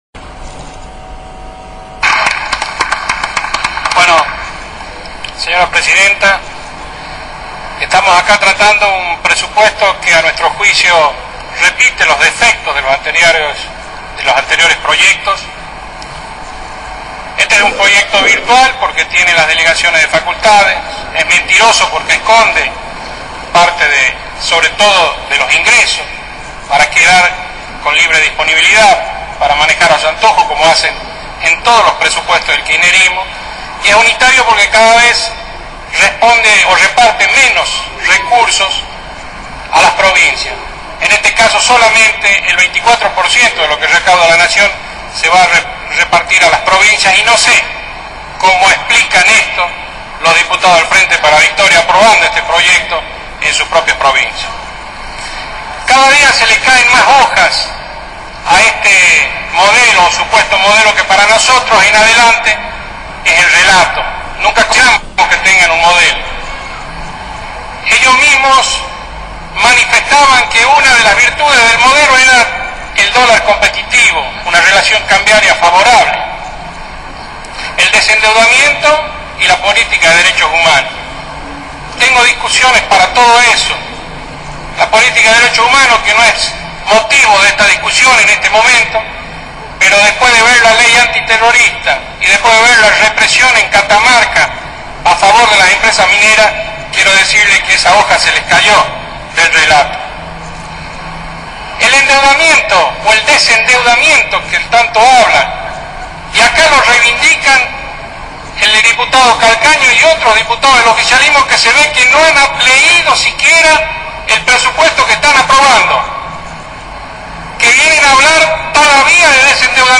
El discurso del diputado Julio Martínez en el Congreso de la Nación